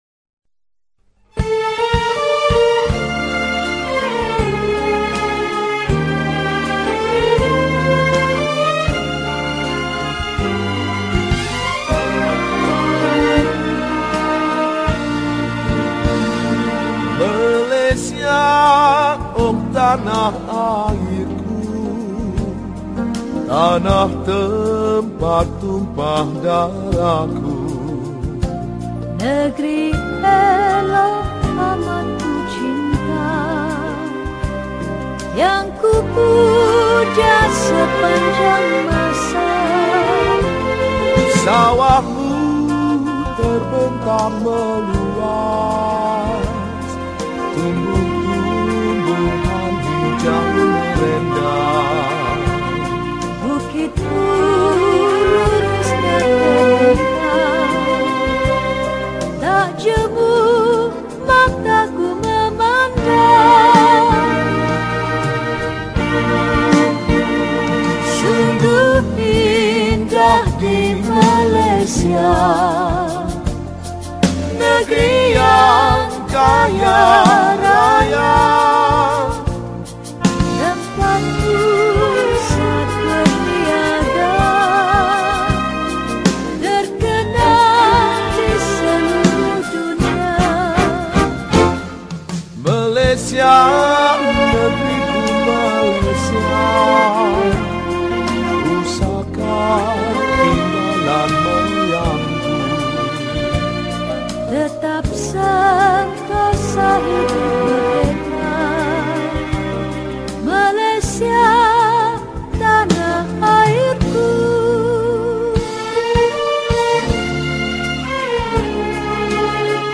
Patriotic Songs
Solo Recorder